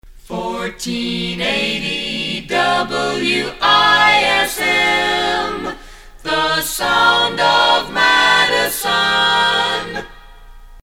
A capella
NOTE: These jingle samples are from my private collection.